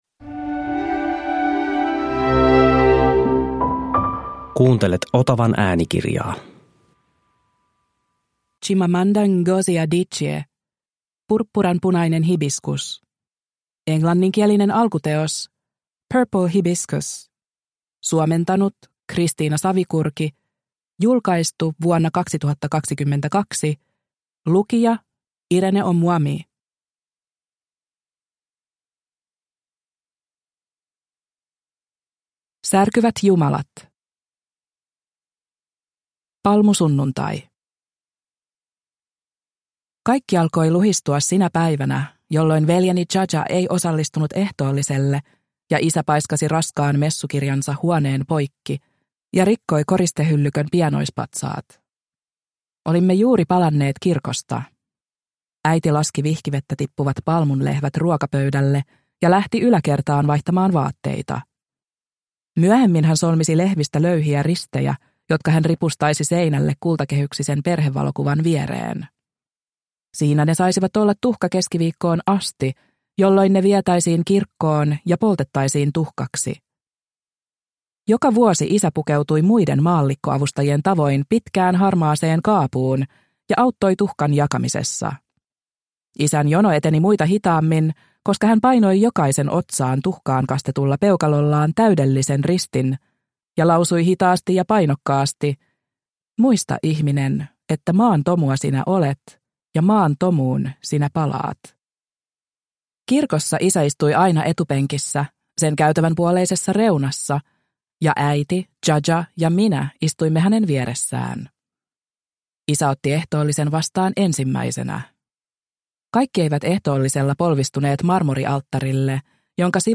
Purppuranpunainen hibiskus – Ljudbok – Laddas ner